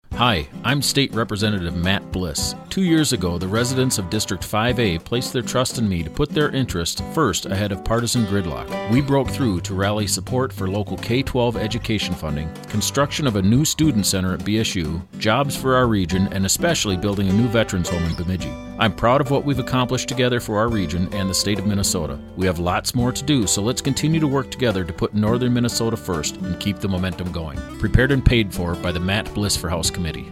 New Radio Spots (Audio & Transcripts)